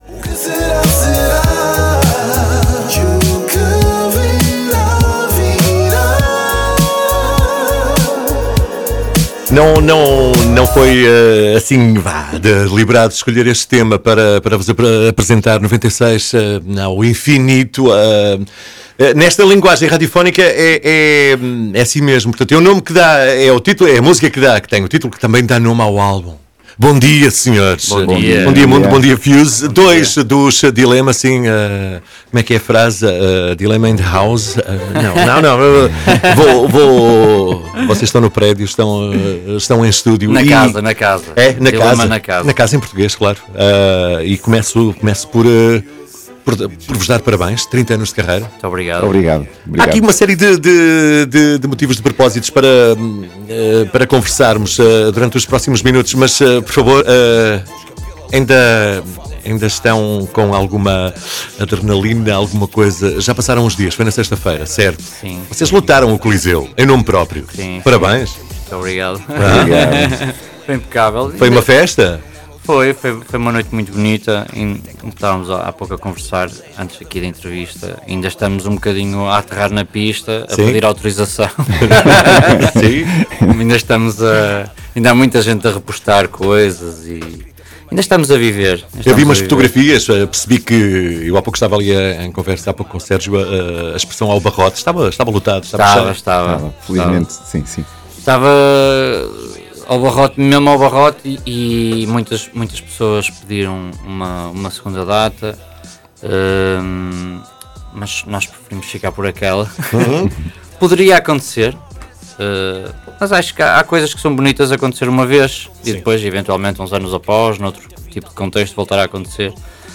Entrevista Dealema